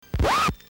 scratch1.mp3